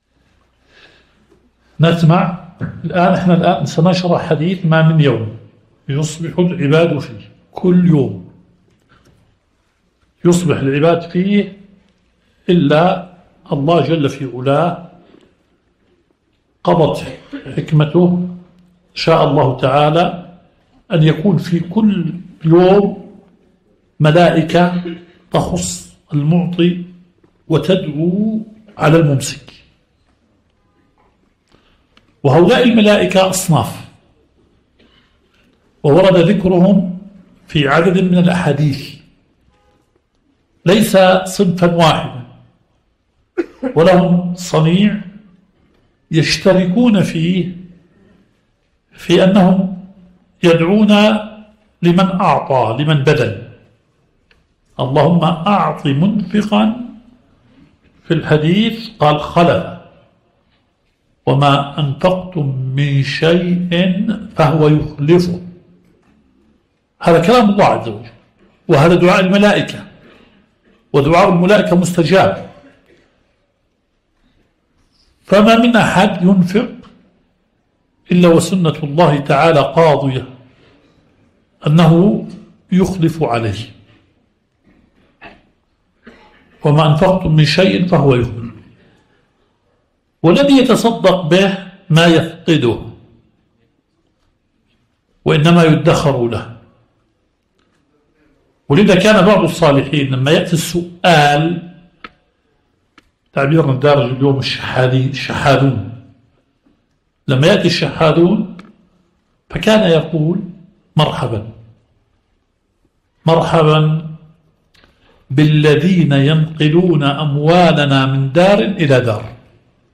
البث المباشر – درس شرح صحيح مسلم